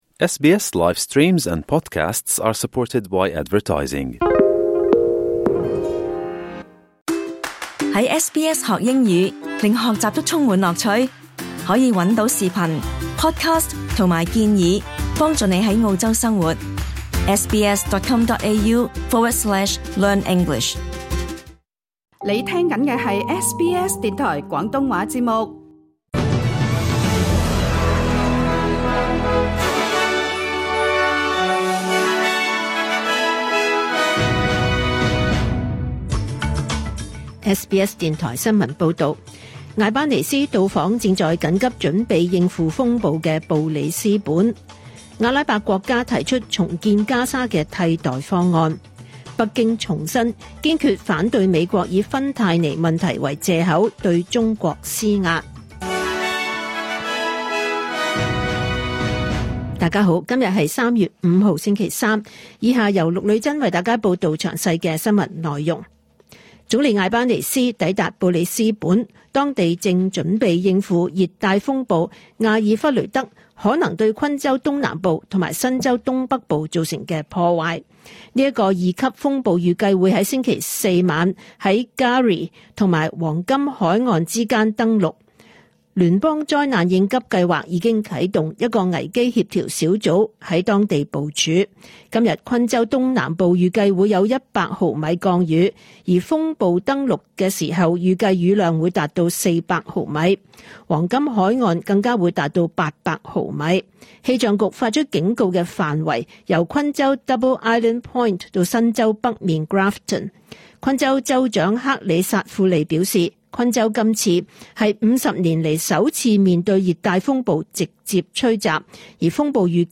2025 年 3 月 5 日 SBS 廣東話節目詳盡早晨新聞報道。